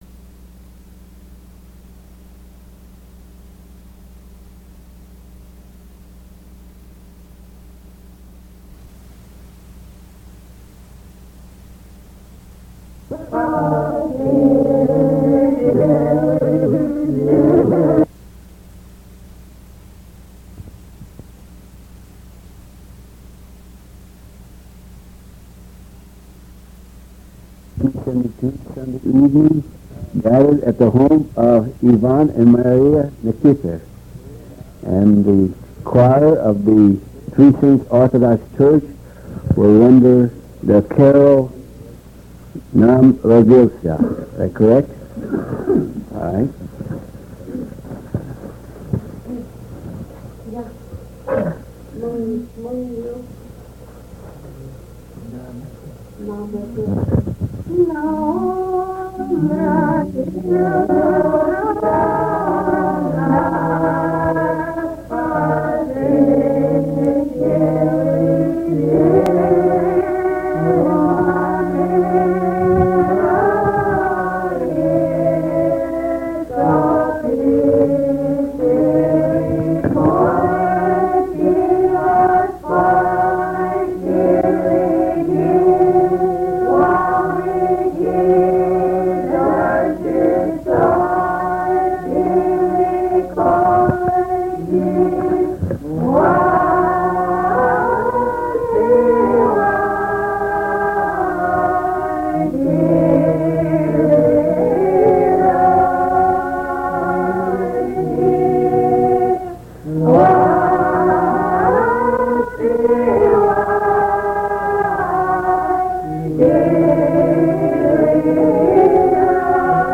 (In English, Russian, and Alutiiq) Description: The church services include a Russian Christmas song, Nama Radiltsa, as sung in Akhiok.